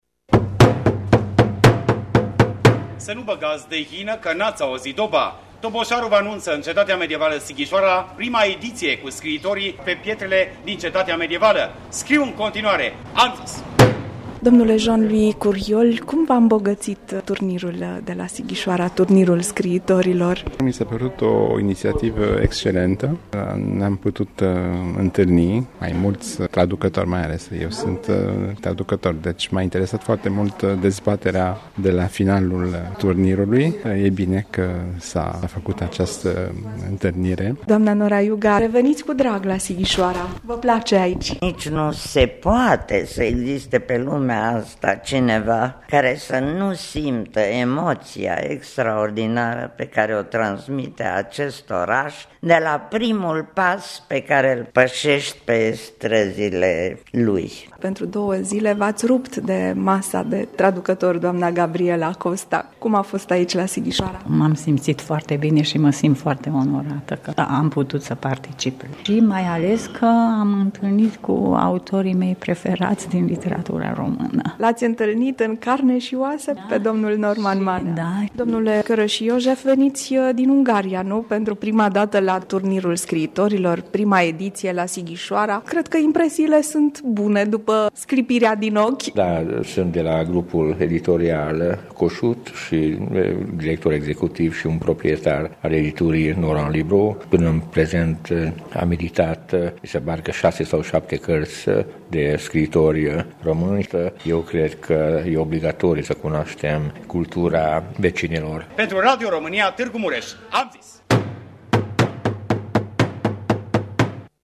Evenimentul care a readus Cetatea Sighișoarei în conul de lumină al Literaturii, a prilejuit întâlniri memorabile cu „scriitori de raftul întâi” și traducători de excepție.